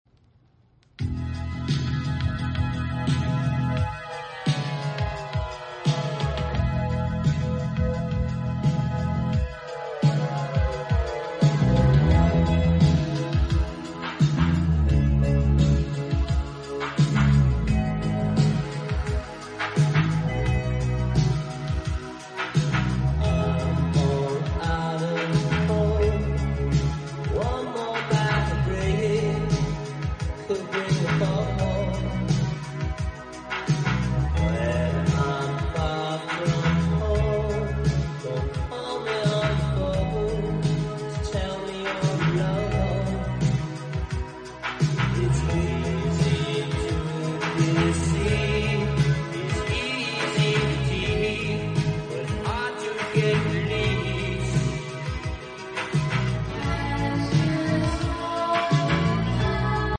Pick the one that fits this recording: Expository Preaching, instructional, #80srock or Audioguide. #80srock